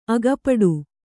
♪ agapaḍu